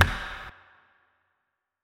TC2 Clap4.wav